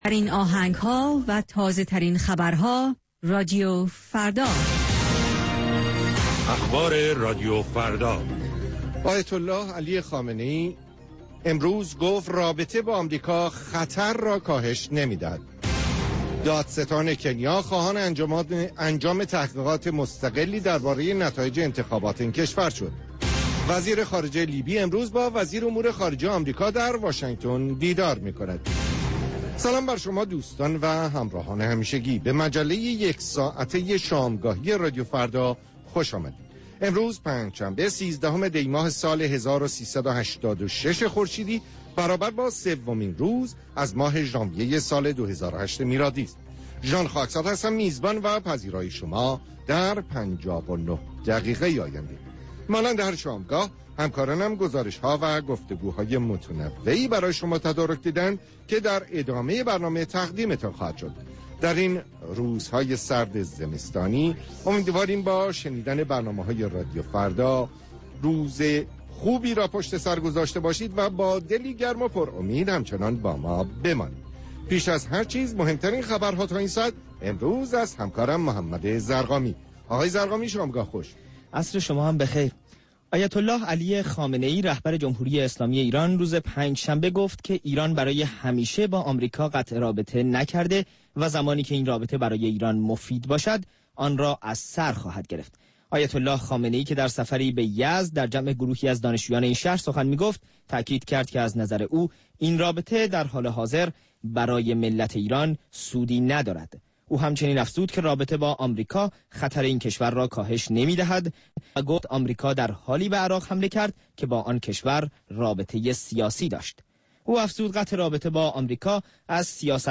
مجموعه ای متنوع از آنچه در طول روز در سراسر جهان اتفاق افناده است. در نیم ساعات مجله شامگاهی رادیو فردا، آخرین خبرها و تازه ترین گزارش های تهیه کنندگان این رادیو فردا پخش خواهند شد.